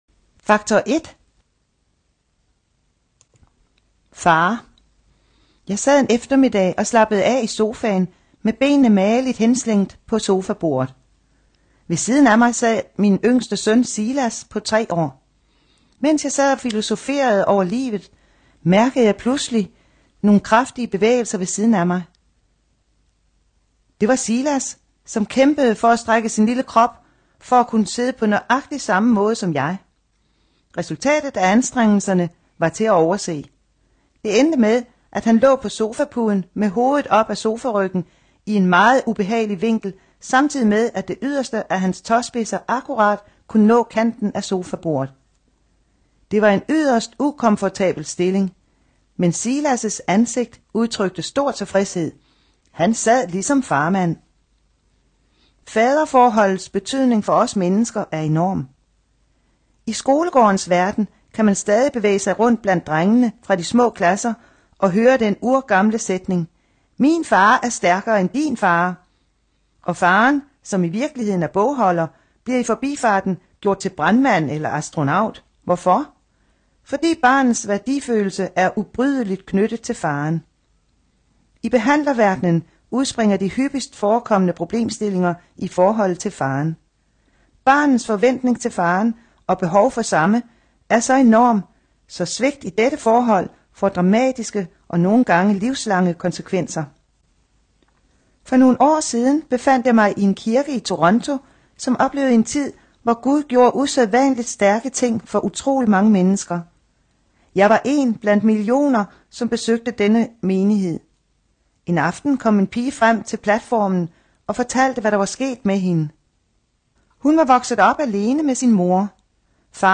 Faktor 10 (lydbog)